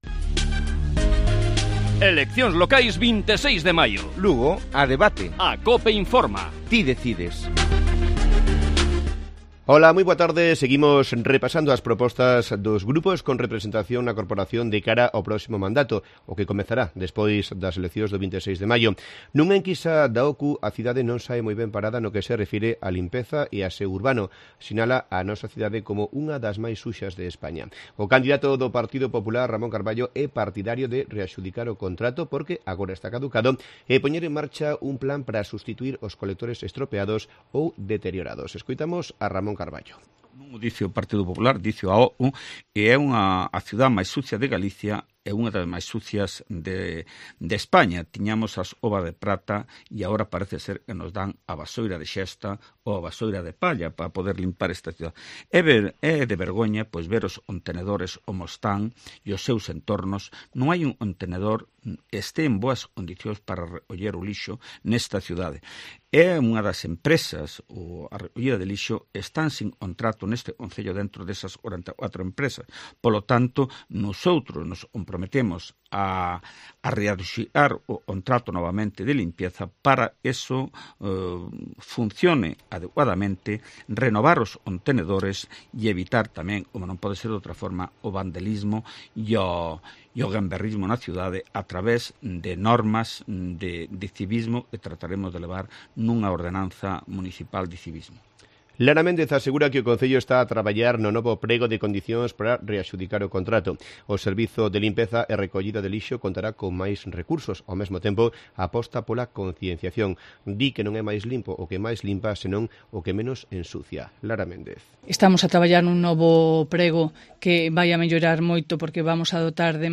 Lugo a debate: Los candidatos hablan sobre limpieza y aseo urbano